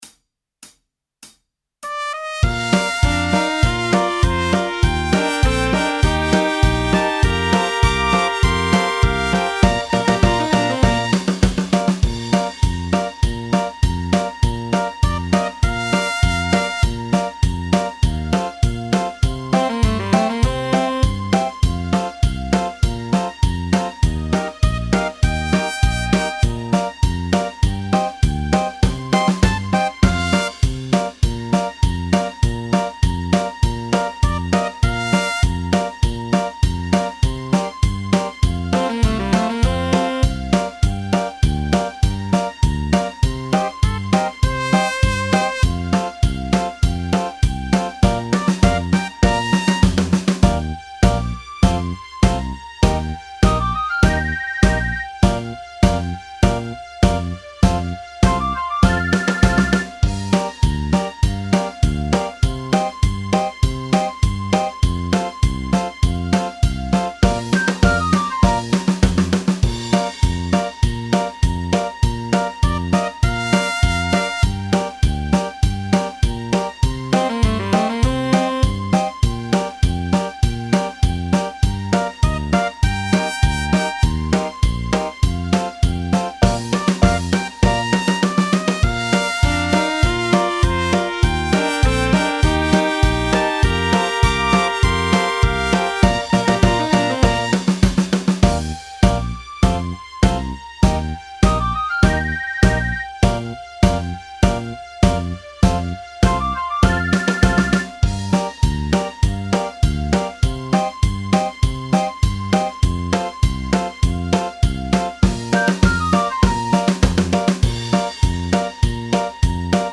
เพลง มาร์ชบ้านเขามัน sound.mp3